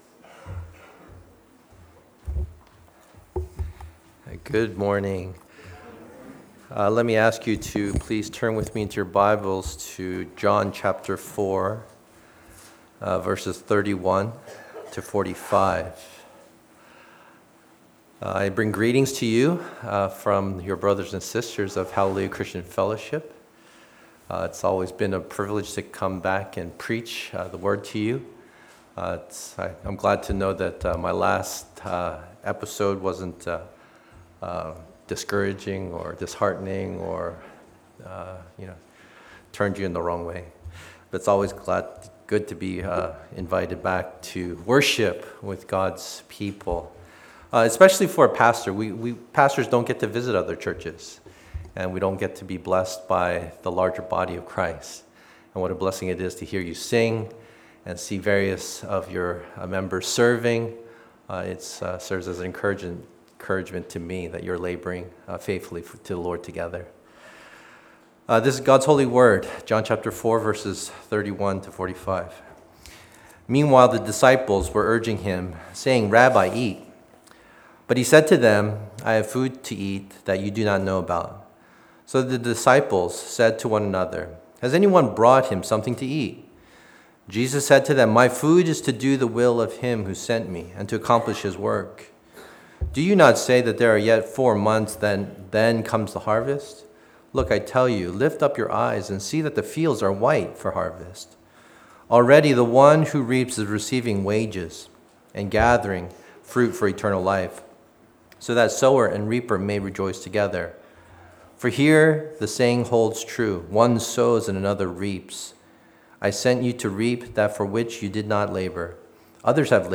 April 17, 2016 (Sunday Morning)